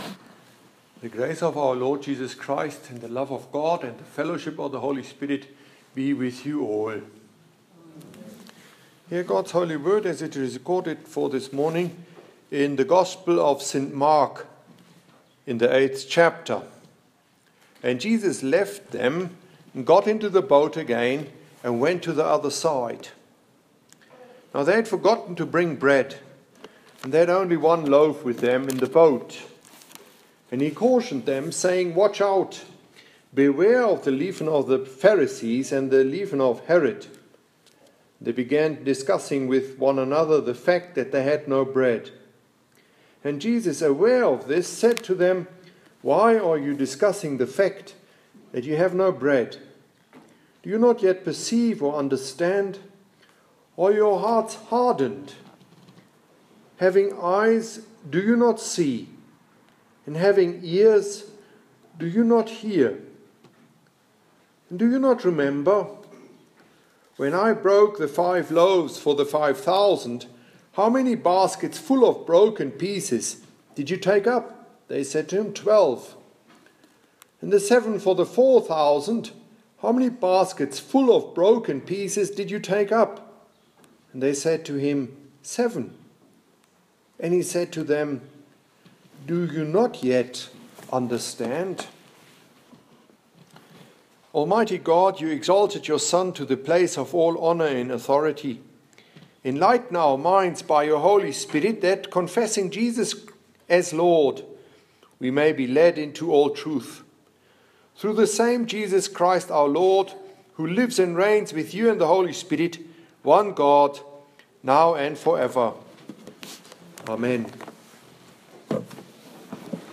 Sermon during Matins